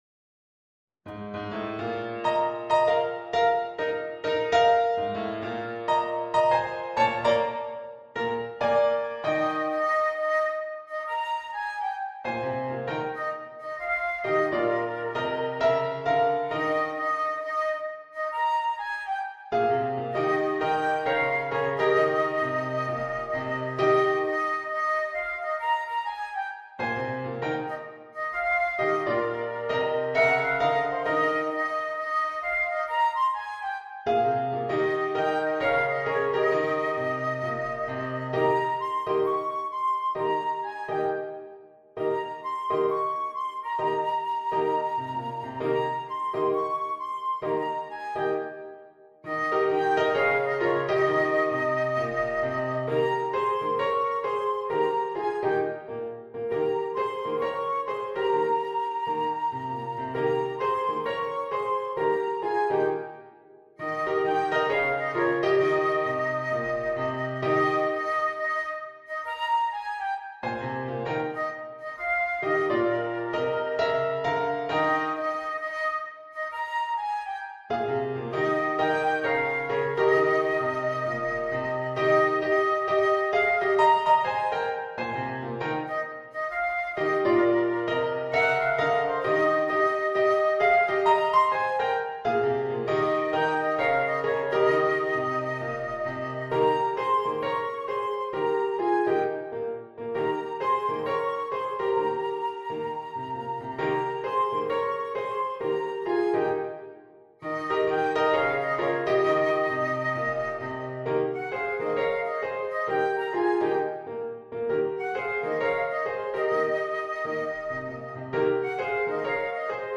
A jazzy, swing edition of traditional American Folk tune
Jazz and Blues